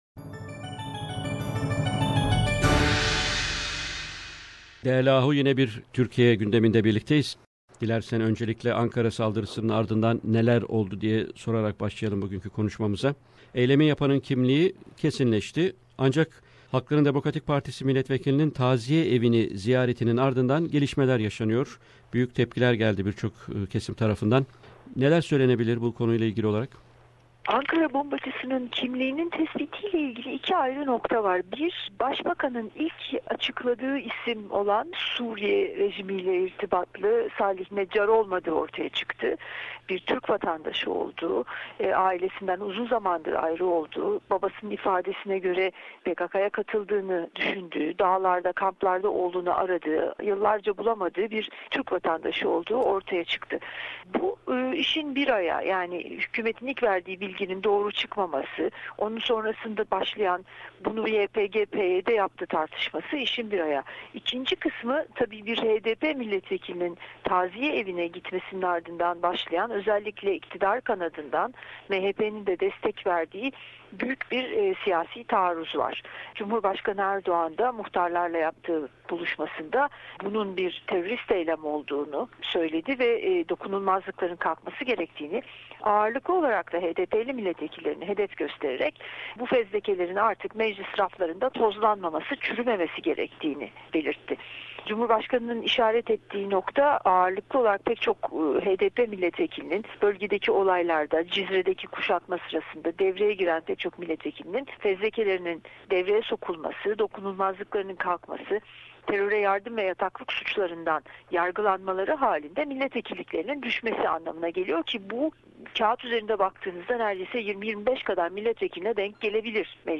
Weekly Stringer Report from Istanbul